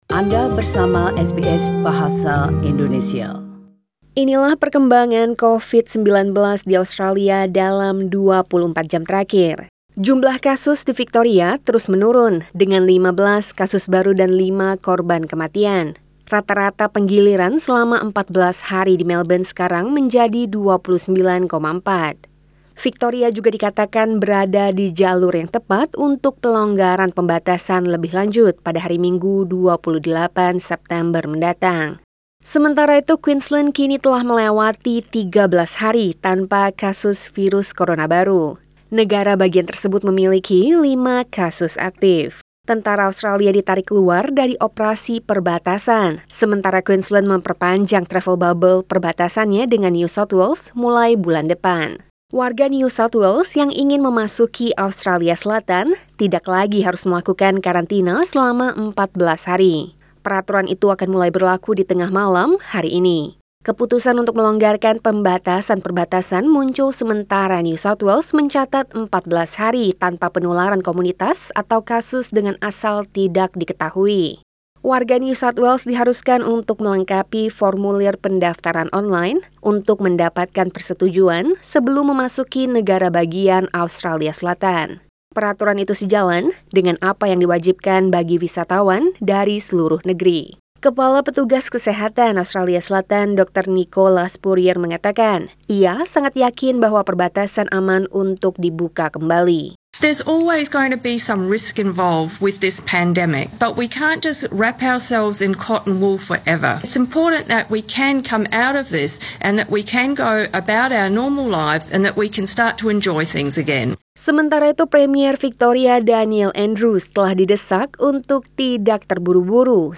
SBS Radio News in Bahasa Indonesia - 23 September 2020